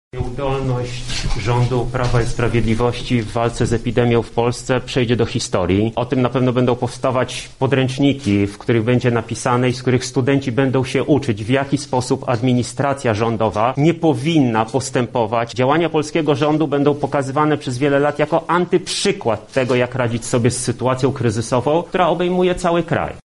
• mówi poseł Koalicji Obywatelskiej Michał Krawczyk.